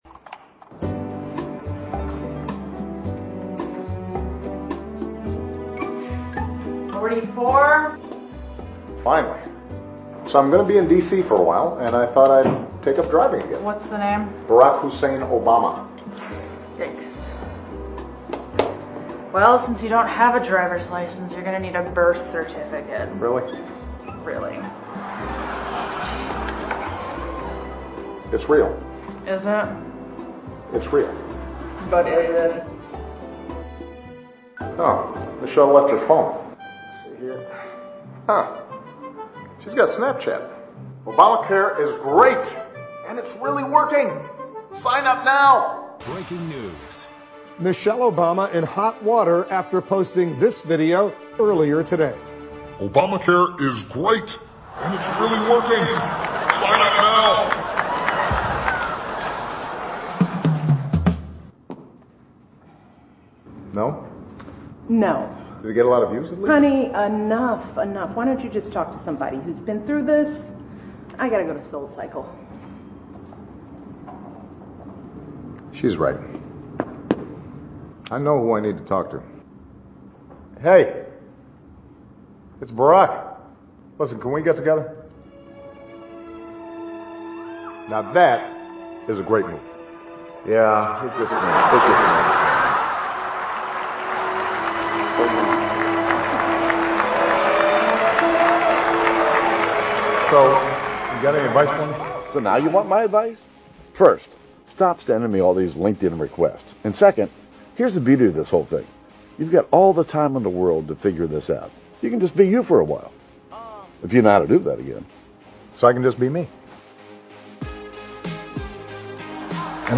欧美名人演讲 第90期:奥巴马任内末次白宫记者晚宴演讲(12) 听力文件下载—在线英语听力室